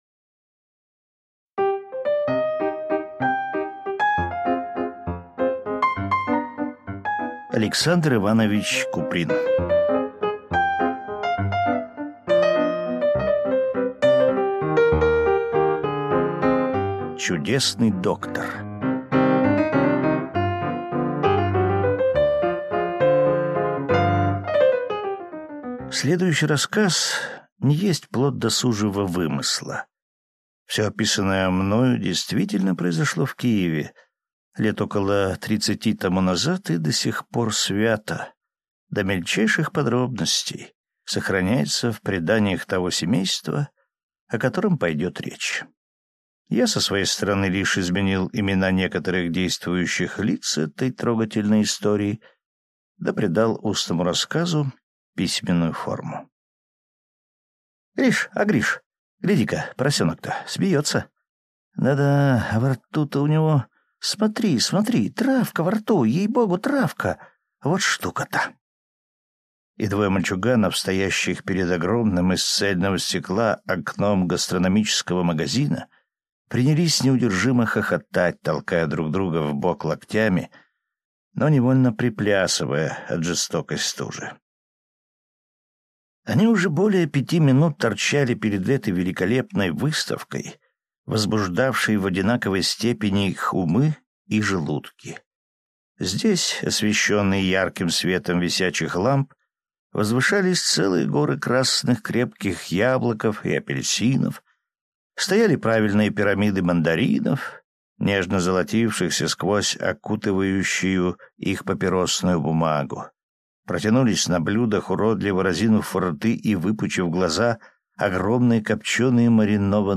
Aудиокнига Чудесный доктор